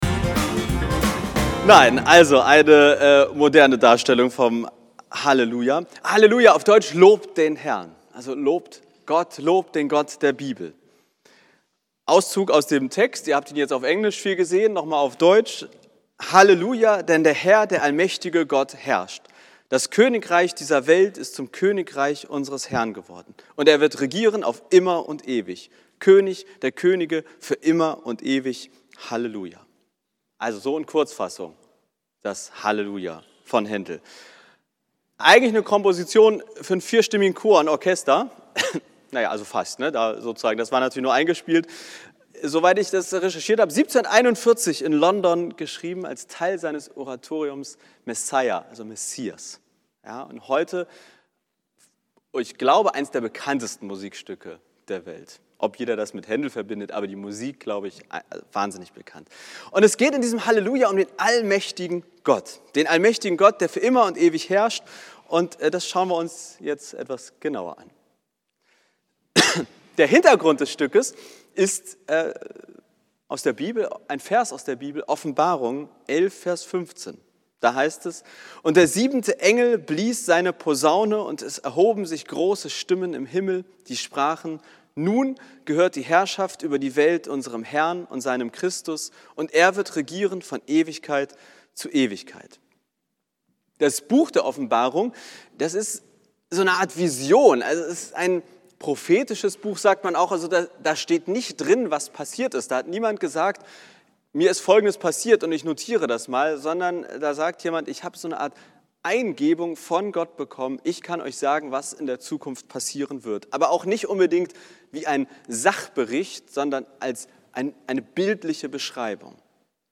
Das „Halleluja“ von Händel ist vermutlich eines der bekanntesten Musikstücke der Welt. Anhand dieses Stückes geht es in meiner letzten Predigt des Jahres 2024 um die Frage, ob Gott das Böse eigentlich besiegt HAT, oder ob er es erst noch besiegen WIRD.